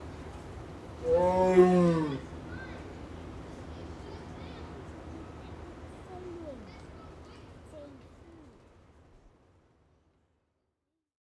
tiger-roar.wav